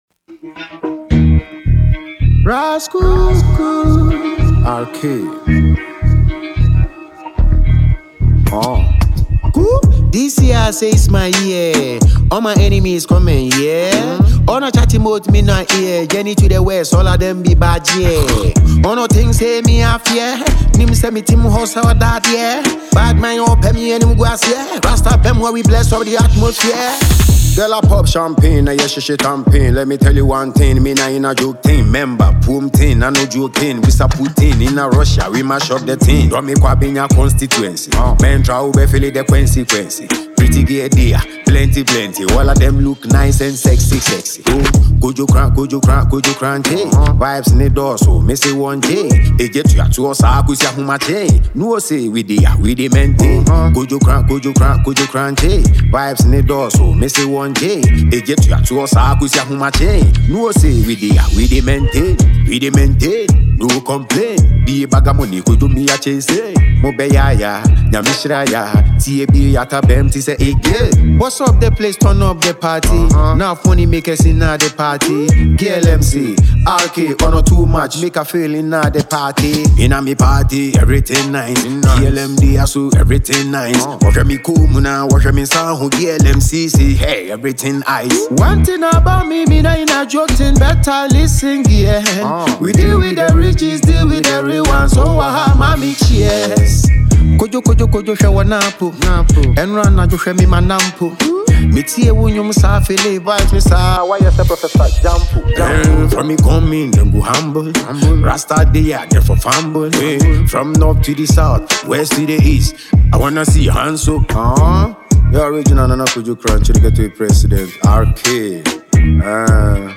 reggae/dancehall
and commanding vocal presence.
Genre: Reggae / Dancehall